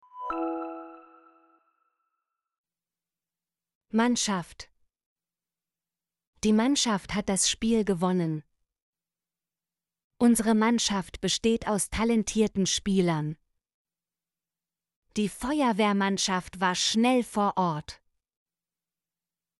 mannschaft - Example Sentences & Pronunciation, German Frequency List